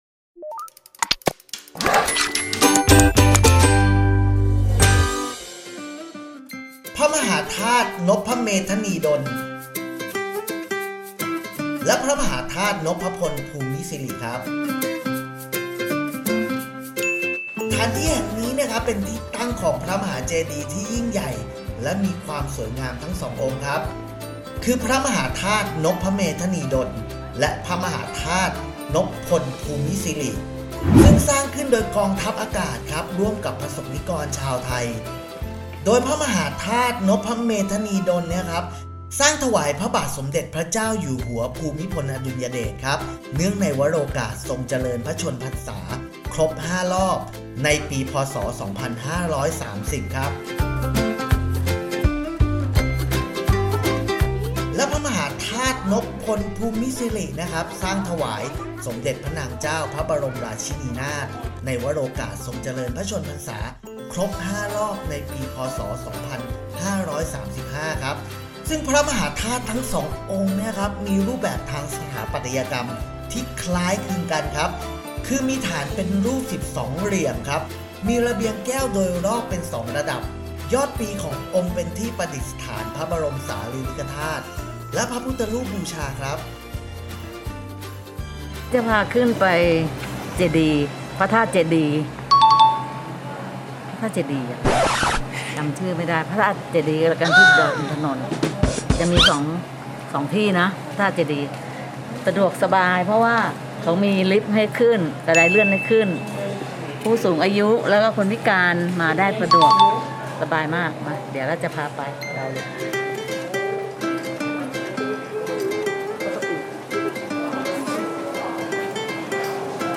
เสียงบรรยายภาพ
AD-สักการะ-พระมหาธาตุนภเมทนีดล-พระมหาธาตุนภพลภูมิสิริ-ที่ดอยอินทนนท์-Wheelwego-Ep.59-128-kbps.mp3